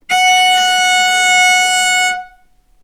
vc-F#5-ff.AIF